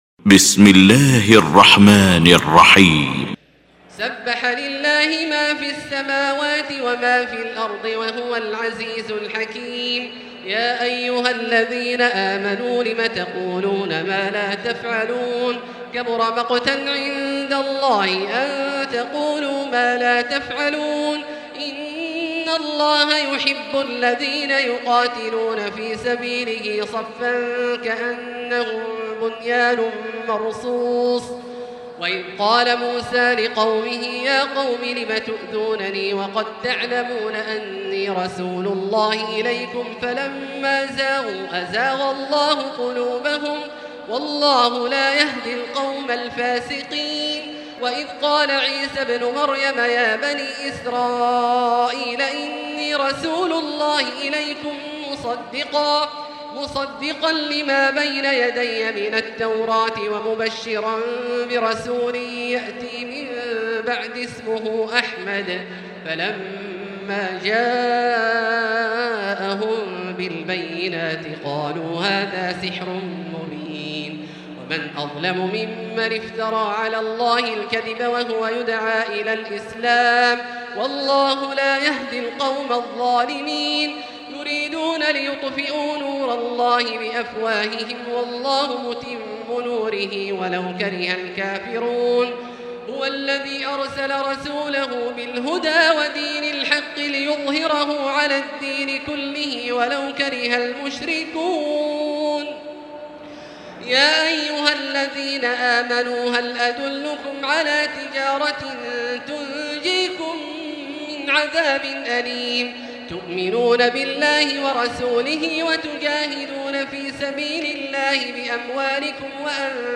المكان: المسجد الحرام الشيخ: فضيلة الشيخ عبدالله الجهني فضيلة الشيخ عبدالله الجهني الصف